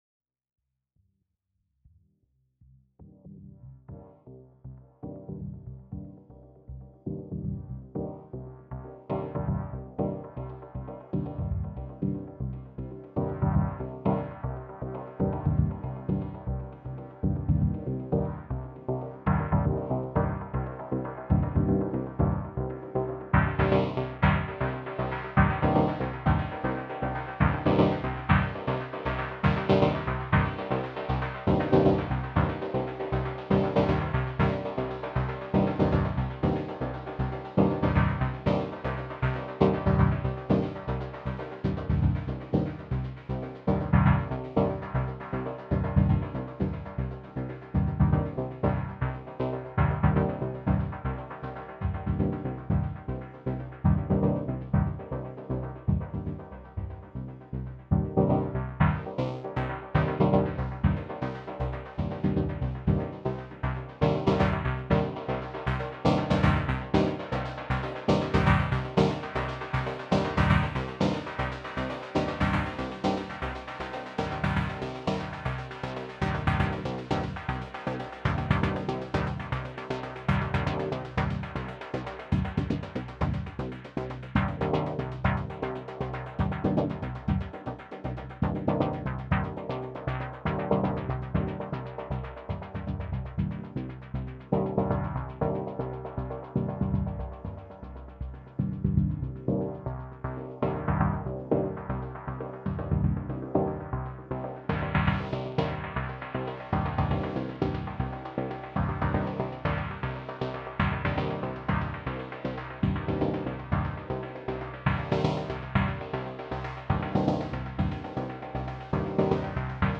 Klingt echt „schlapp und gurkig“ Welche Prophet Gurke war das?